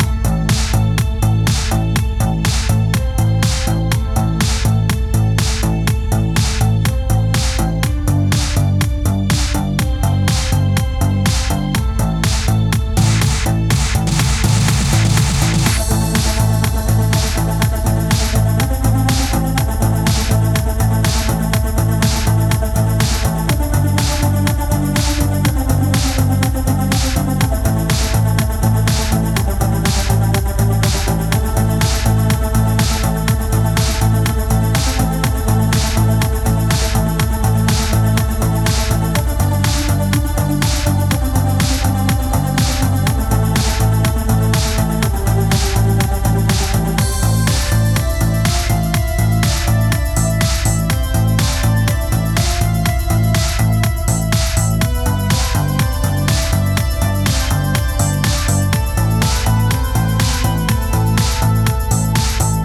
hallo, ich habe einen song (elektronische musik) versucht mit hall zu mischen. leider kommt die tiefenstaffelung nicht zum tragen. ich gehe folgender...
hallo, ich habe einen song (elektronische musik) versucht mit hall zu mischen. leider kommt die tiefenstaffelung nicht zum tragen. ich gehe folgender maßen vor: instrumente die hinten stehen ,pad, strings, bekommen einen large reverb und einen large room mit einem predelay von 14 ms...
es kann sein das die lautstärkeverhältnisse nicht korrekt sind. der synth bass der vorne steht der kommt vorne nicht richtig zur geltung.